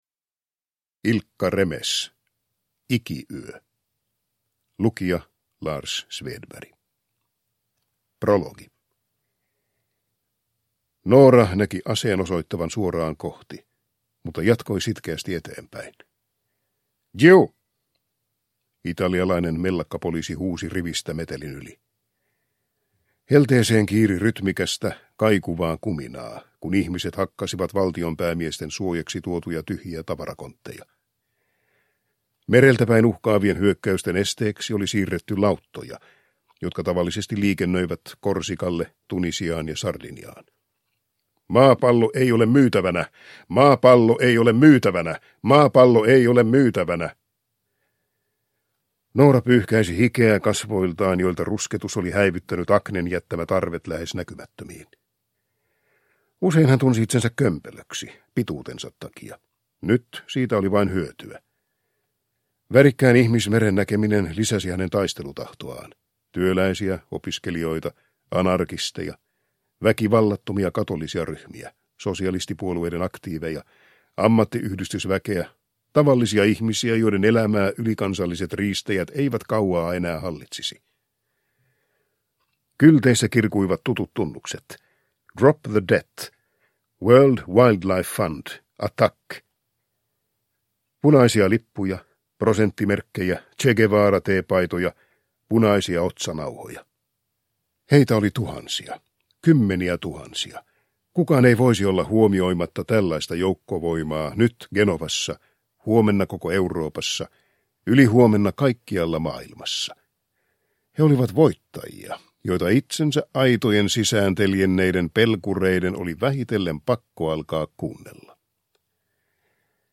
Ikiyö – Ljudbok – Laddas ner